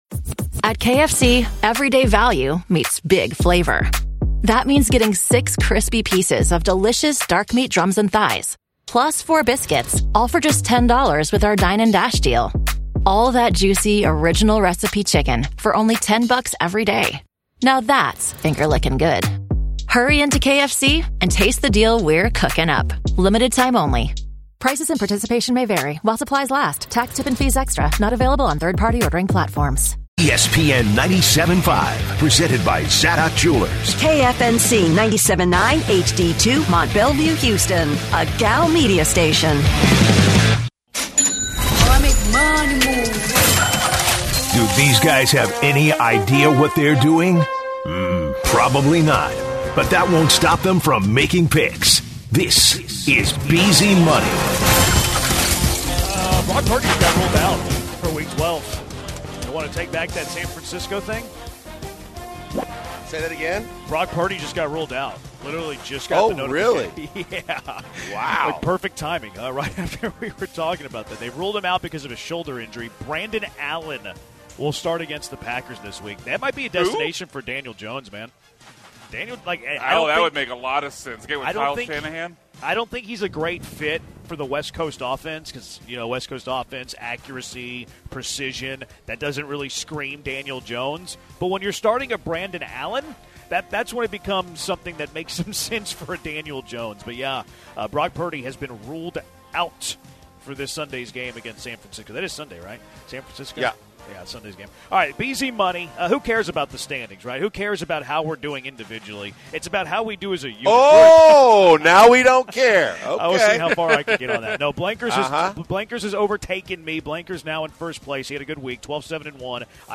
11/22 Hour 2- Live From Twin Peaks, Talk College Football Rankings and What's to Come From the Astros